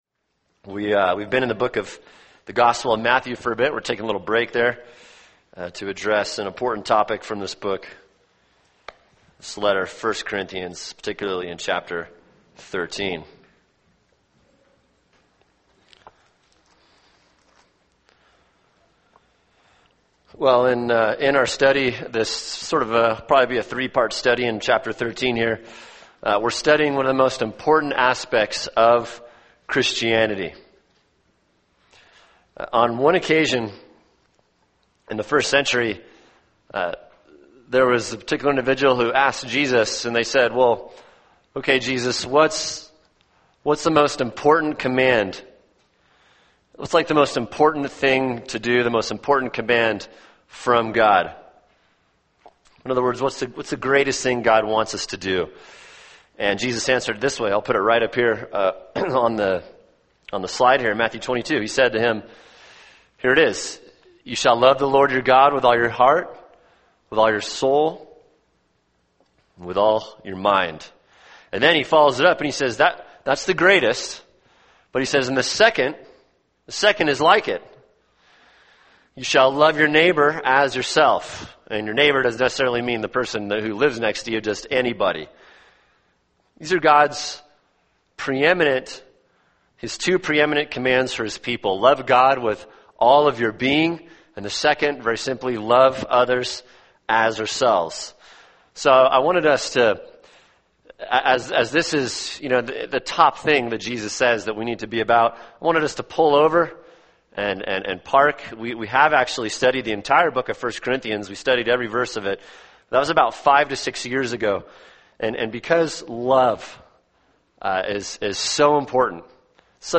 [sermon] 1 Corinthians 13:1-7 – Love (part 2) | Cornerstone Church - Jackson Hole